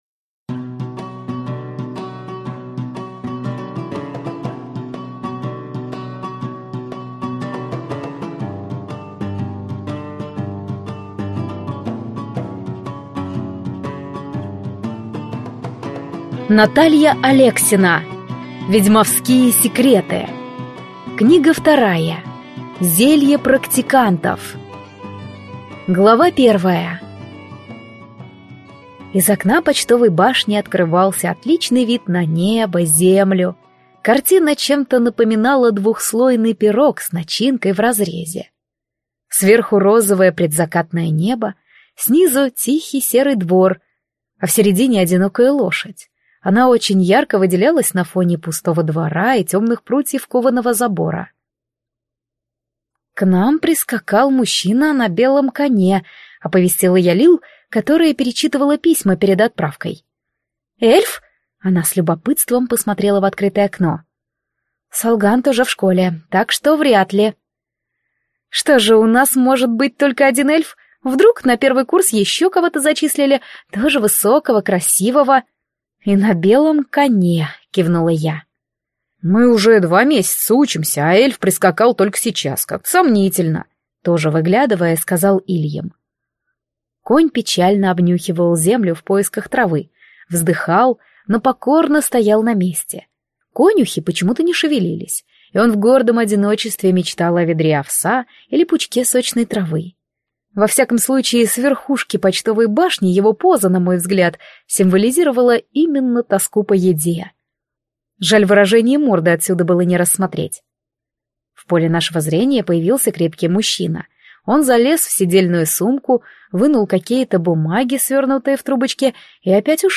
Аудиокнига Зелье практикантов | Библиотека аудиокниг